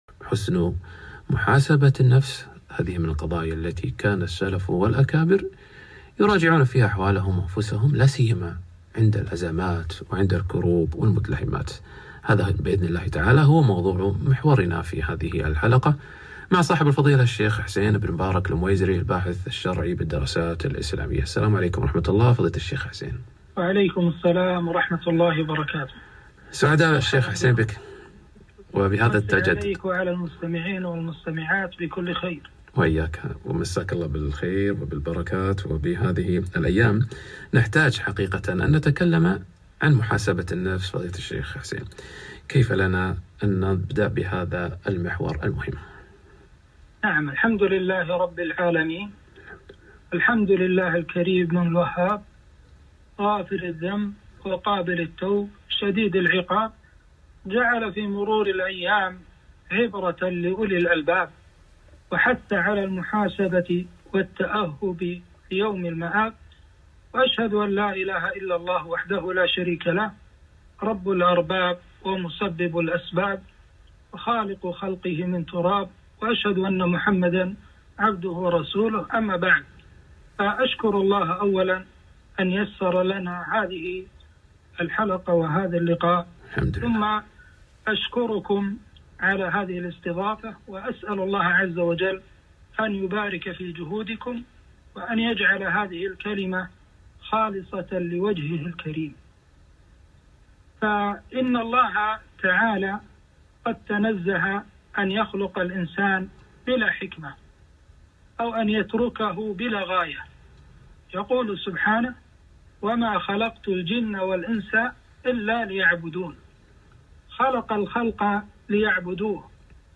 محاسبة النفس - لقاء إذاعي